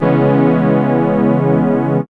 1807R SYNSTR.wav